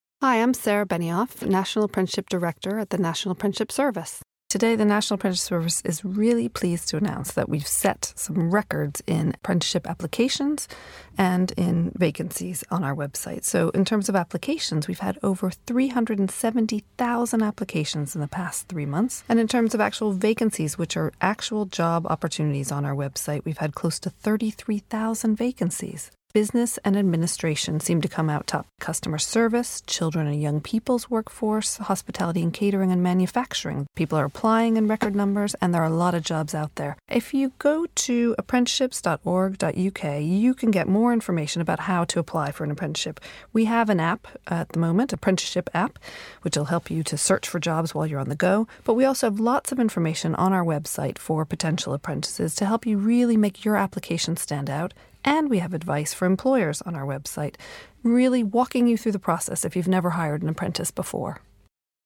joined us in the On Broadcast studio